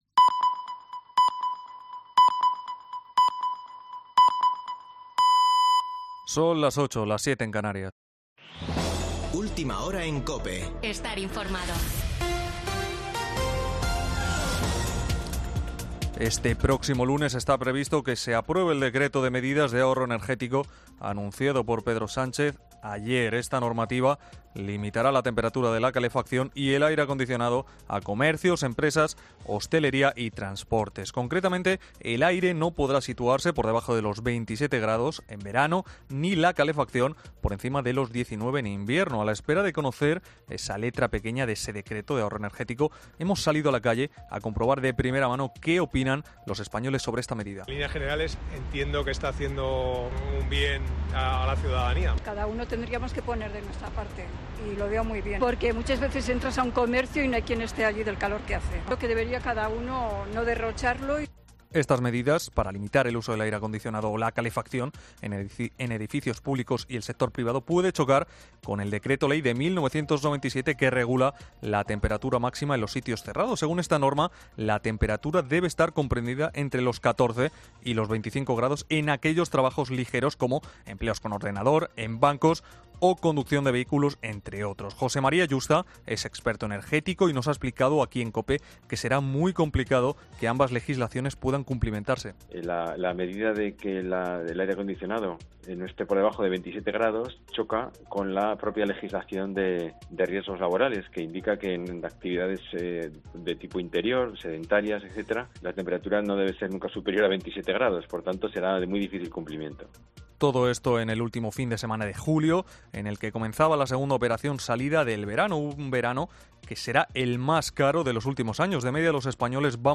Boletín de noticias de COPE del 30 de julio de 2022 a las 20.00 horas